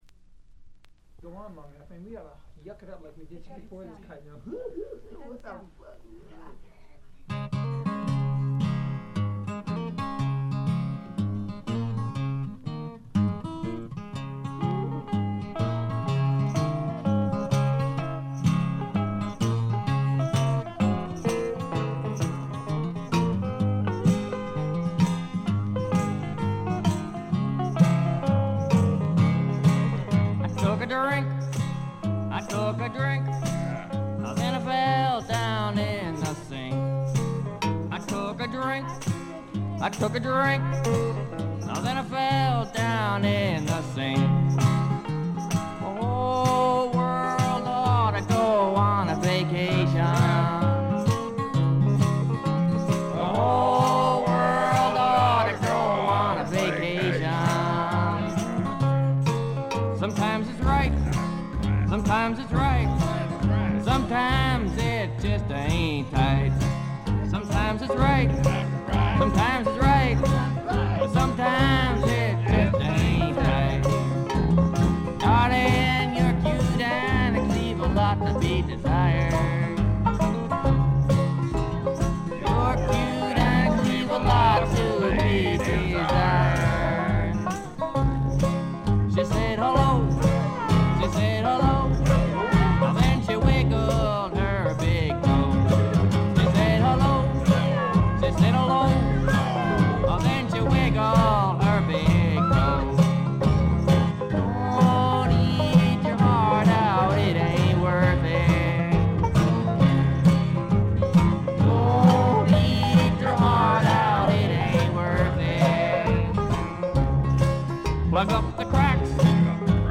軽微なバックグラウンドノイズ。散発的なプツ音が少し。
試聴曲は現品からの取り込み音源です。